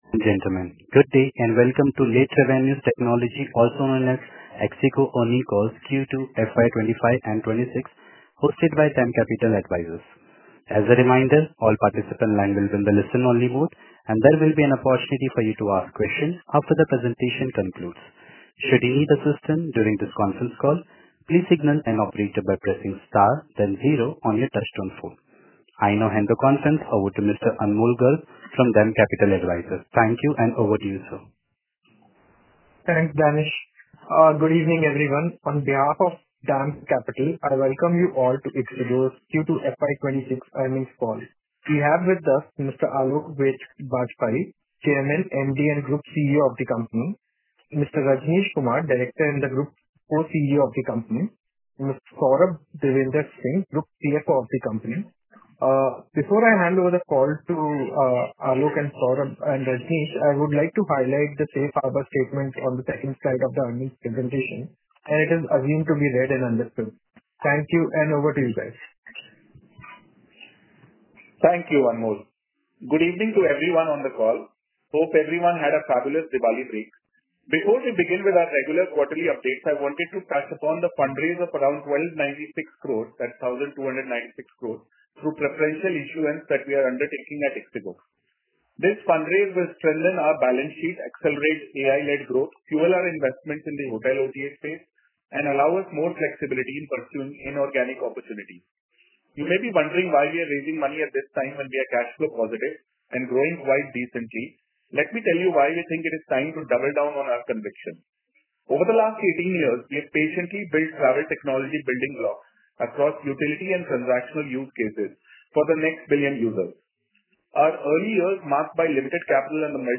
Latest Results Q4 FY25 Earnings Investor Presentation → Earnings Release & Results → Earnings Media Release → Earnings Call Recording → Earnings Call Transcript → Earnings Data Book → Earnings Data Book (Excel) →